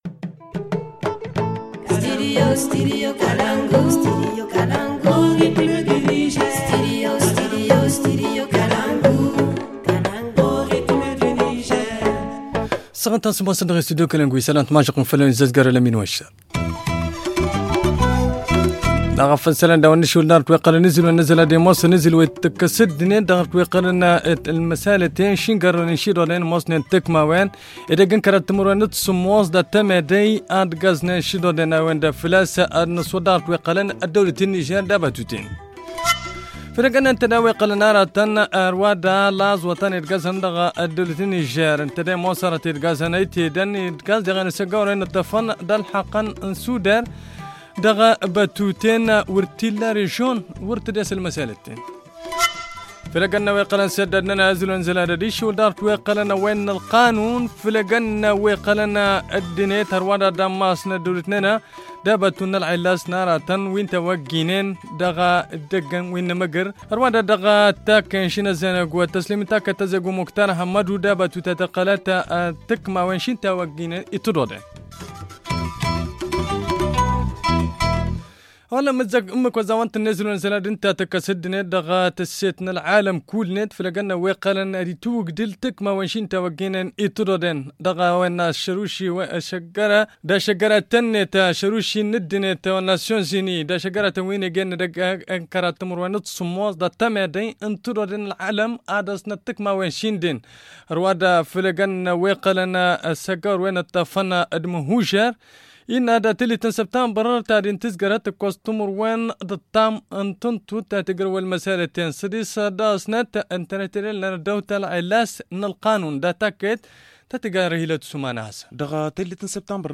Le journal du 25 Novembre - Studio Kalangou - Au rythme du Niger